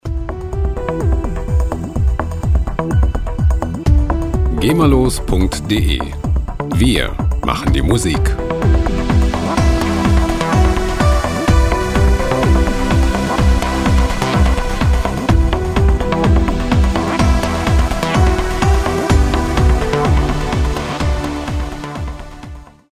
gema-freie Loops aus der Rubrik "Trance"
Musikstil: Progressive Trance
Tempo: 126 bpm